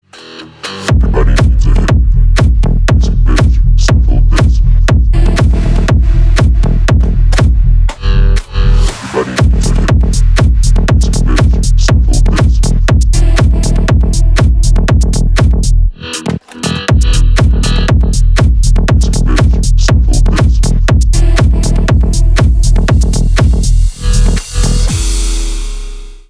Танцевальные
клубные # громкие